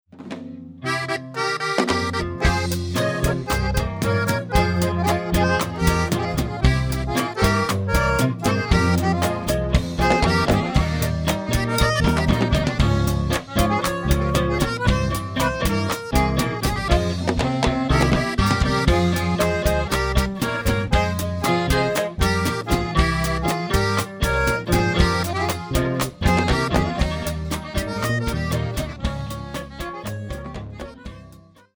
The Texas-Mexican Conjunto
accordion
banjo sexto
bass guitar
drums, vocals